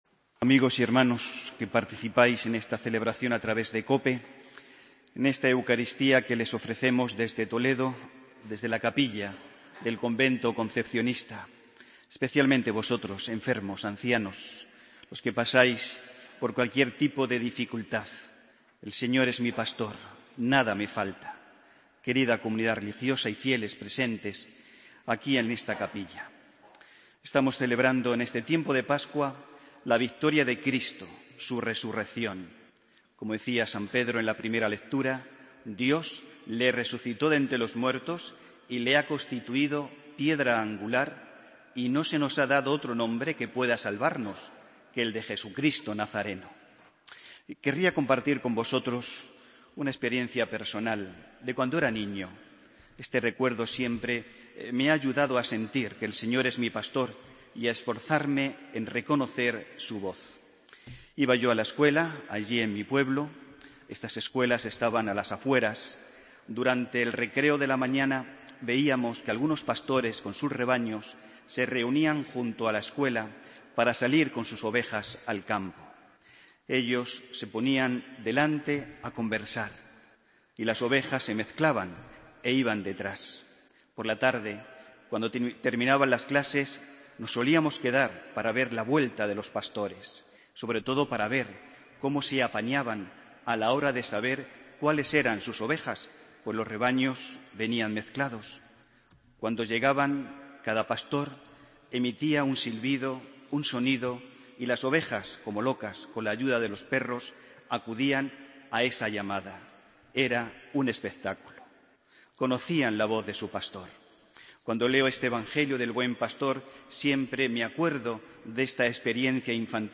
Homilía del domingo 26 de abril de 2015